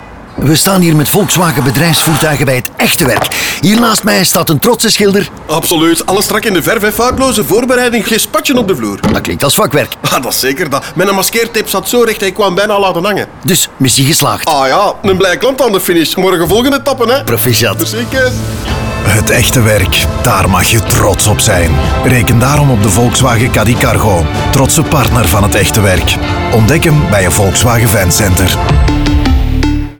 En ook op radio staan de trotse vakmensen centraal in verschillende reeksen van 3 radiospots waarin vakmensen met trots over hun job praten als waren het fiere sportmannen na een topprestatie.
Schilder_NL.mp3